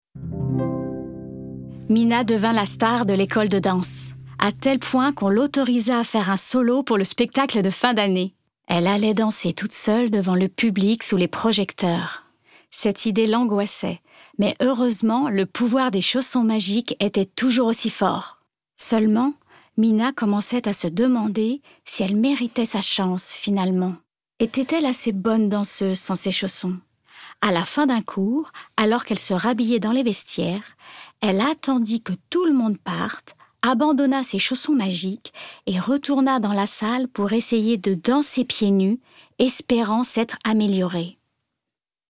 Extrait conte
25 - 38 ans - Soprano